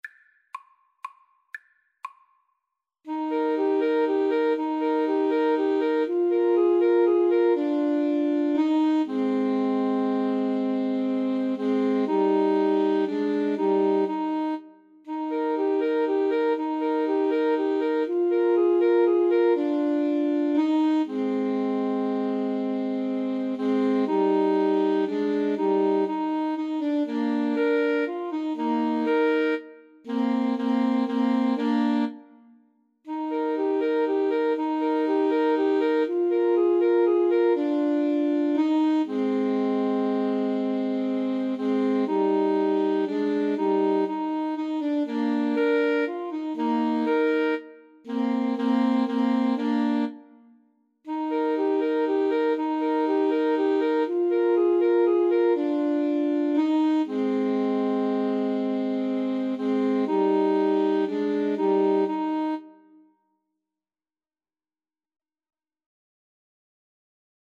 3/4 (View more 3/4 Music)
Alto Sax Trio  (View more Easy Alto Sax Trio Music)
Classical (View more Classical Alto Sax Trio Music)